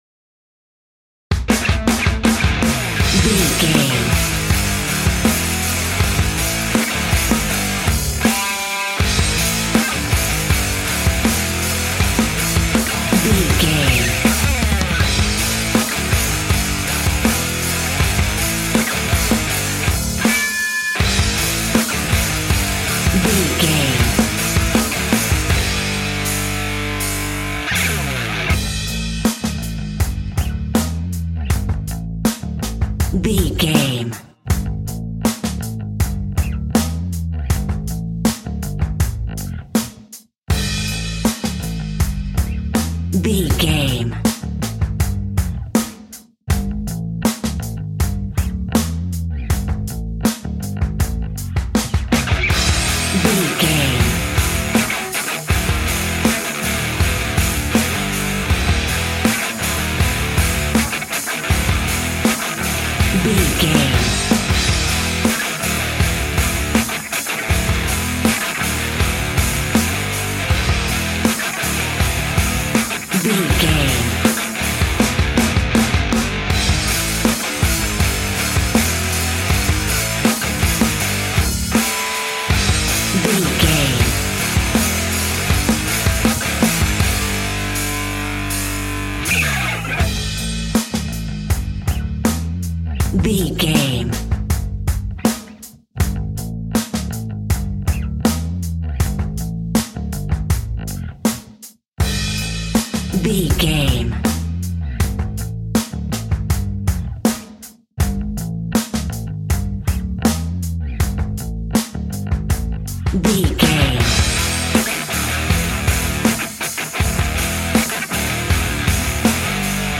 Epic / Action
Dorian
hard rock
guitars
heavy metal
rock instrumentals
Heavy Metal Guitars
Metal Drums
Heavy Bass Guitars